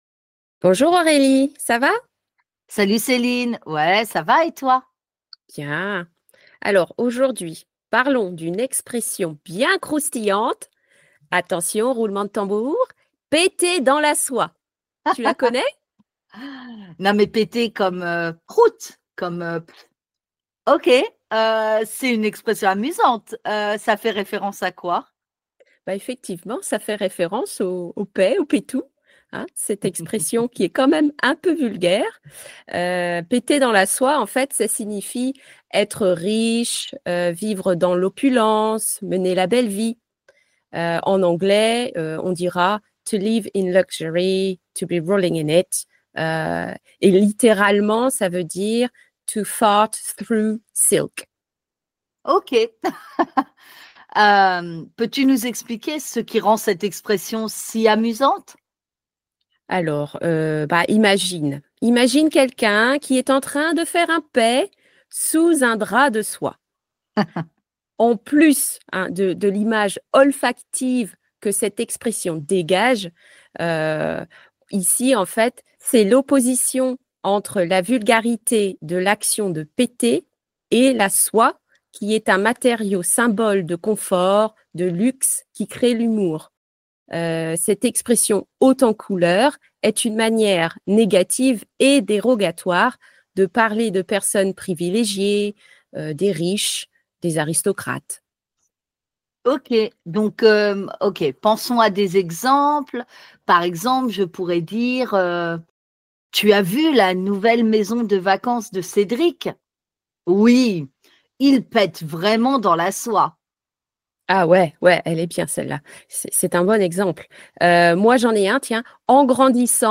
This French native speaker comes from Brittany, and likes crafts, Breton dance (of course!) and Breton music which she actually played for four years.